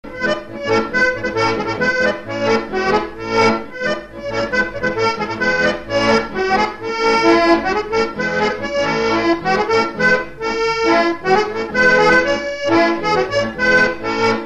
Courante
Résumé instrumental
danse : branle : courante, maraîchine
Pièce musicale inédite